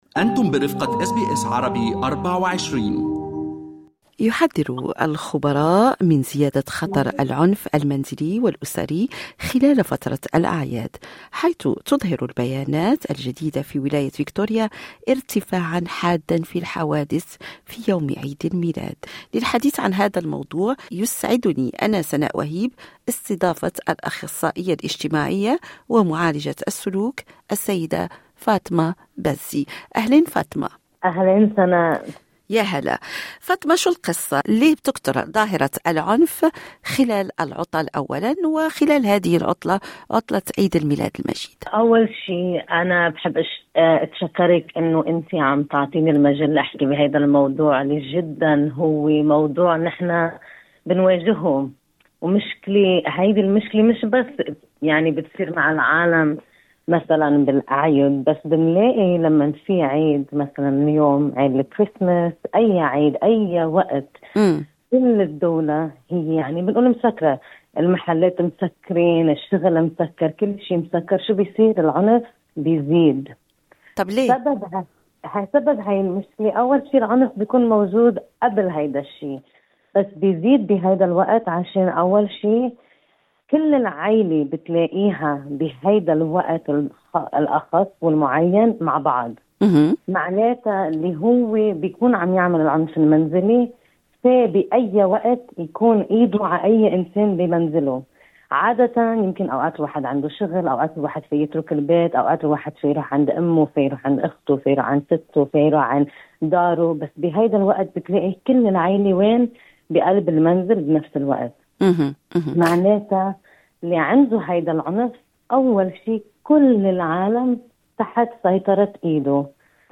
المزيد من التفاصيل في اللقاء أعلاه.